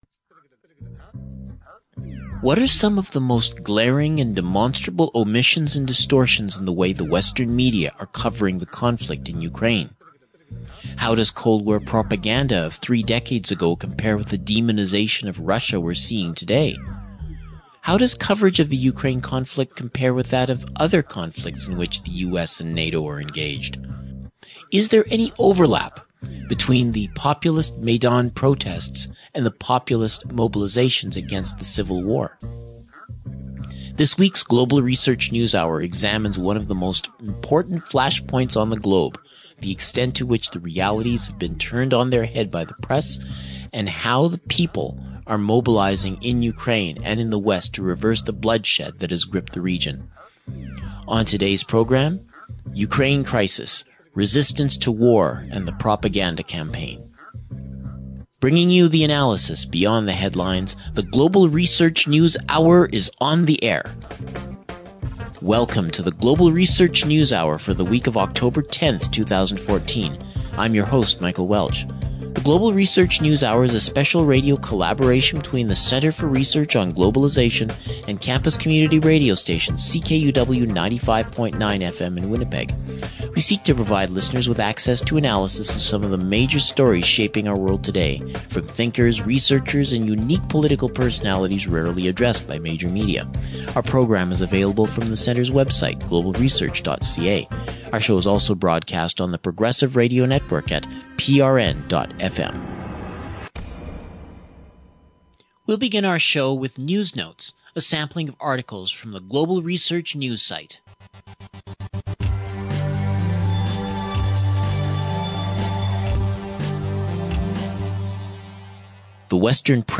Interviews on the propaganda campaign and anti-war efforts